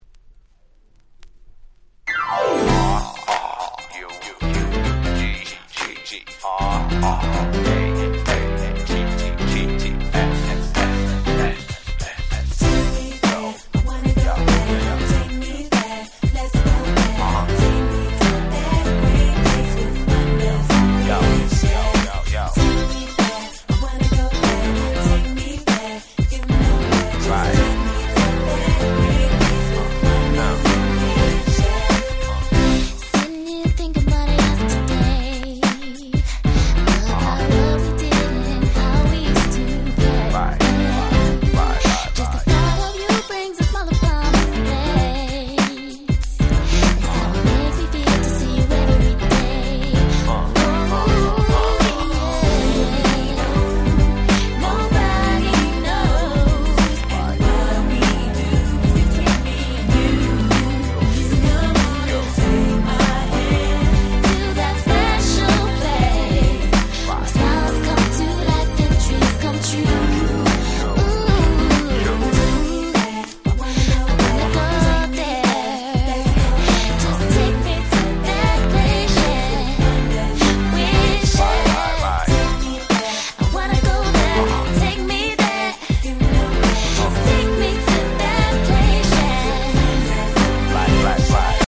99' Super Hit R&B !!